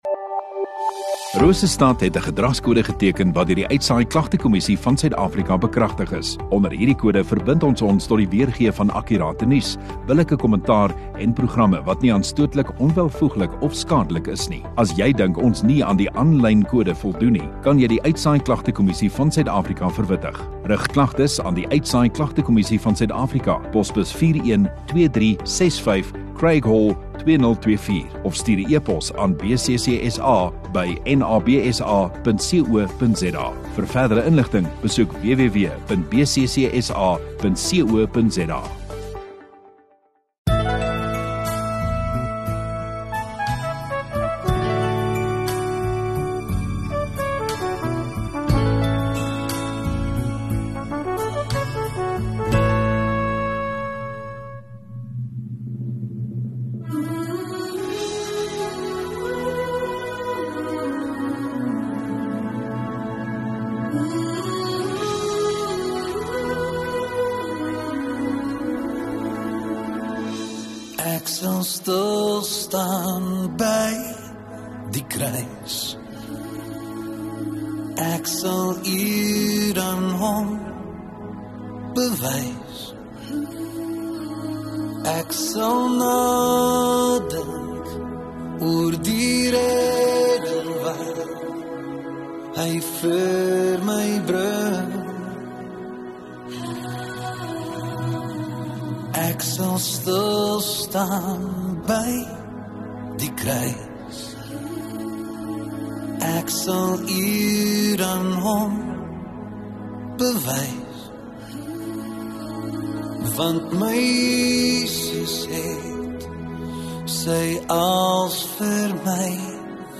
13 Jul Sondagaand Erediens